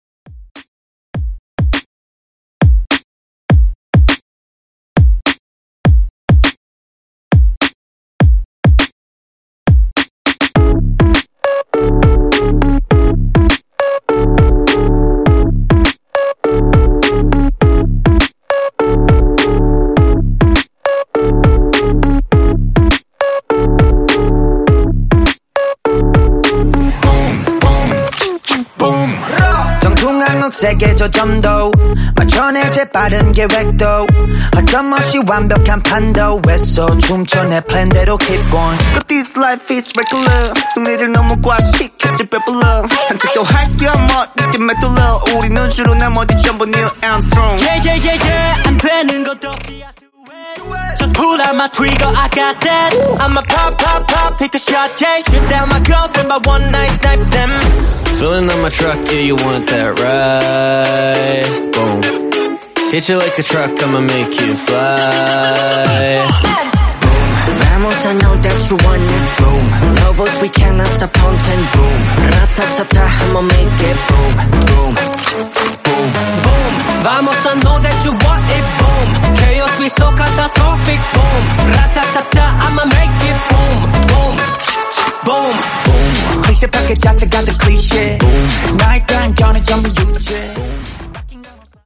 Genres: 80's , MELBOURNE BOUNCE
Clean BPM: 128